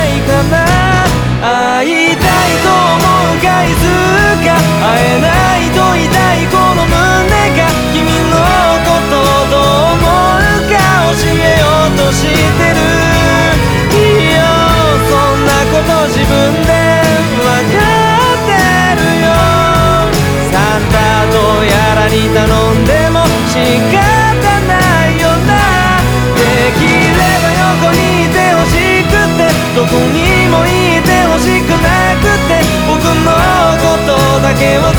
Жанр: Поп / J-pop